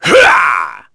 Crow-Vox_Attack4.wav